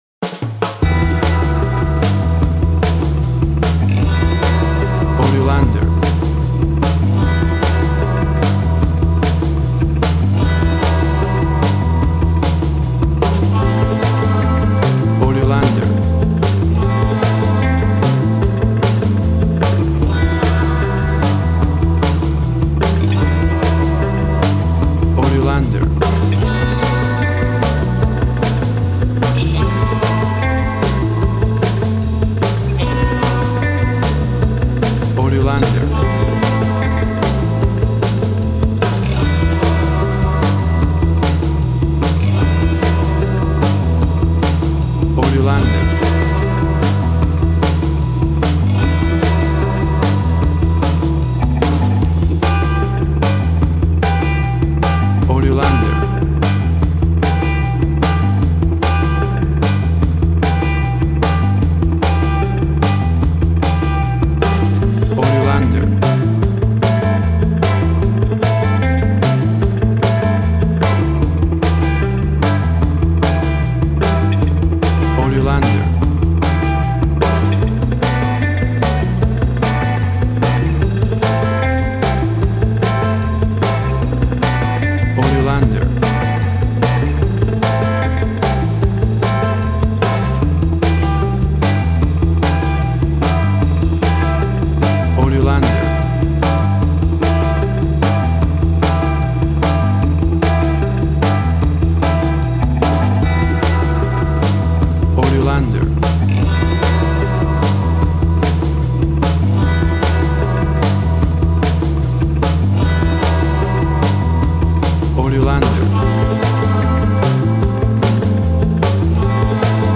Tempo (BPM) 150